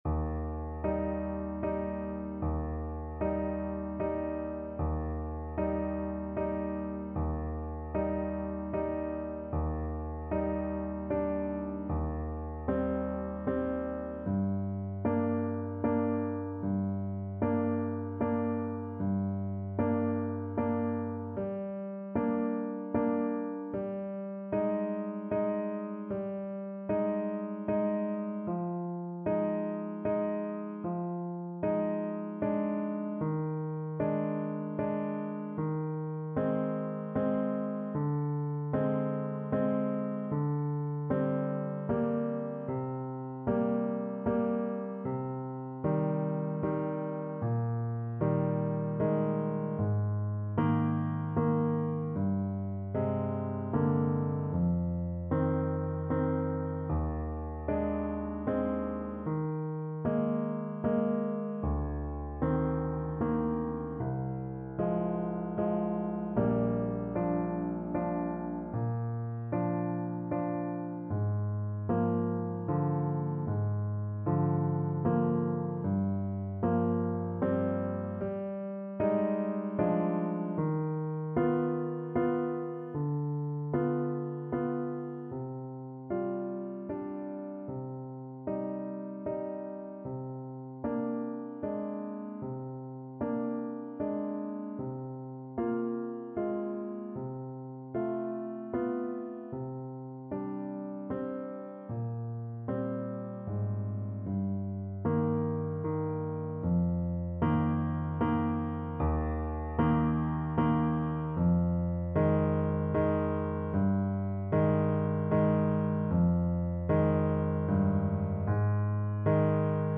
Adagio assai =76
Classical (View more Classical Saxophone Music)